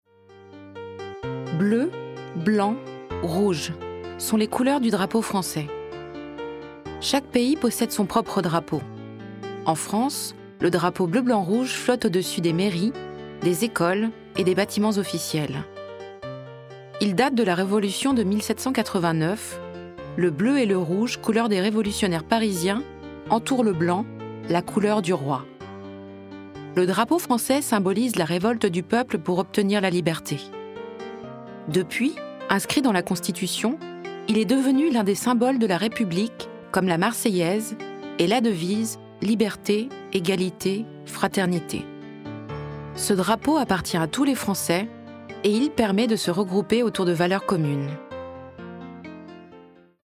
Ma voix est celle d’une femme entre trente et quarante ans qui a du coeur, de l’humanité, de la luminosité et une douceur qui convient par exemple très bien à la narration d’un drame historique.
Narration : Bleu, blanc, rouge sont les couleurs du drapeau français.